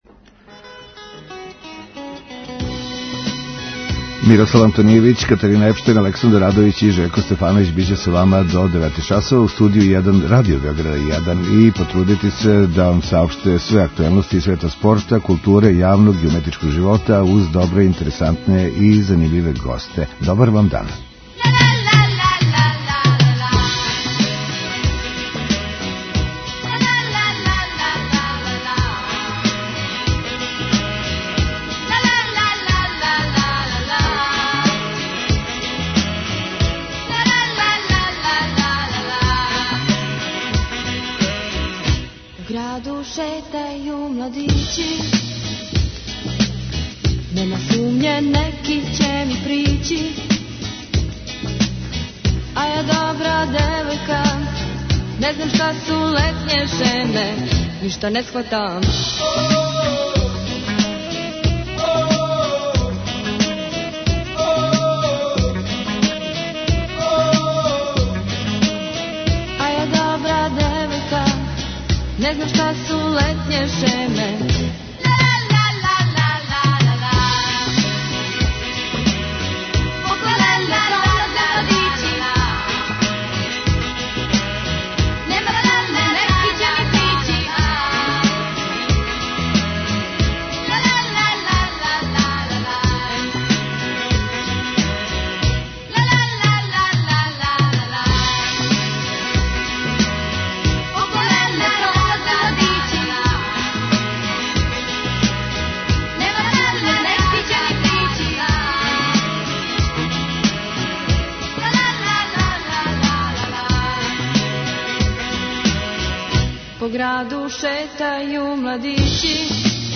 Данашњу емисију започињемо укључењем у пренос полуфиналне утакмице Светске лиге, у којој изабраници Дејана Савића играју са Аустралијом за пласман у финале.